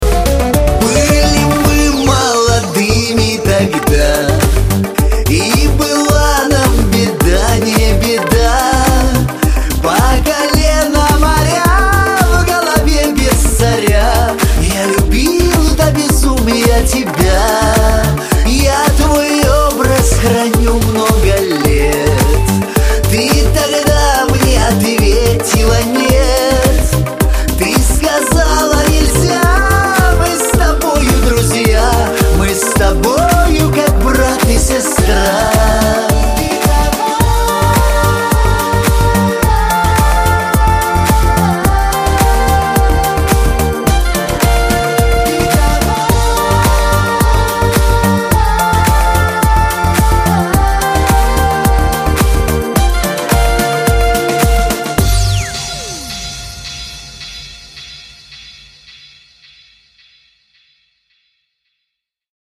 • Качество: 320, Stereo
грустные
русский шансон